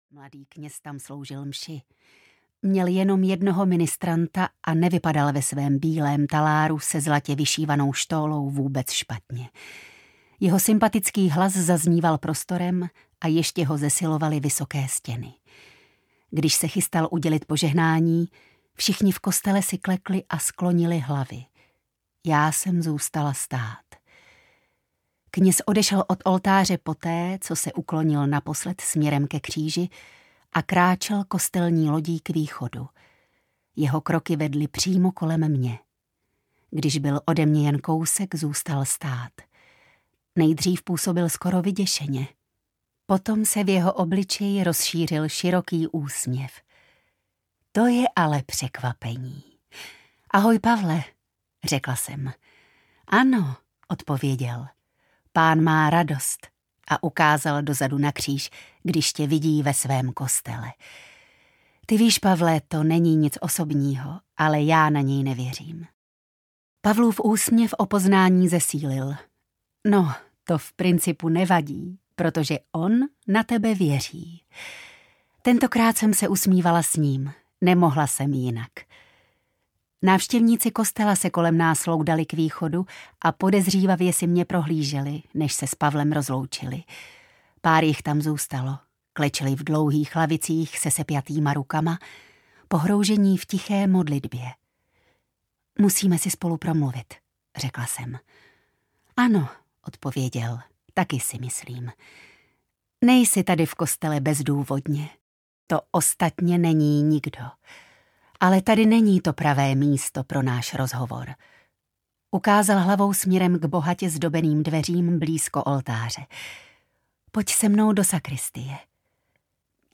Slzy mrtvé jeptišky audiokniha
Ukázka z knihy
slzy-mrtve-jeptisky-audiokniha